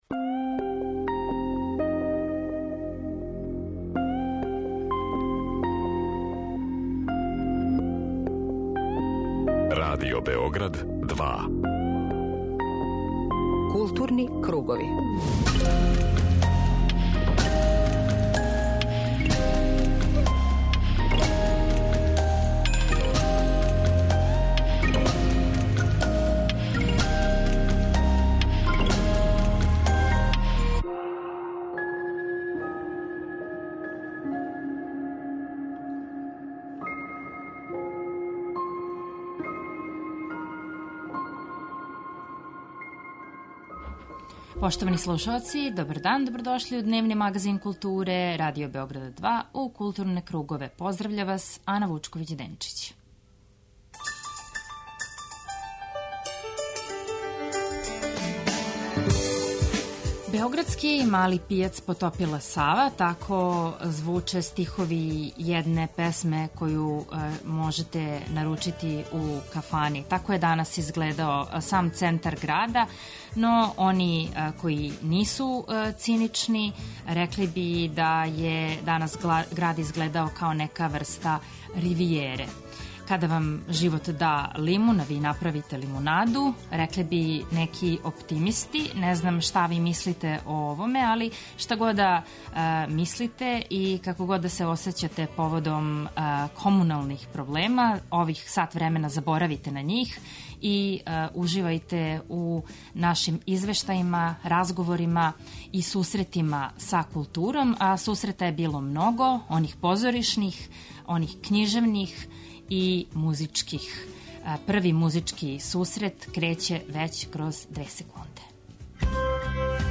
Магазин културе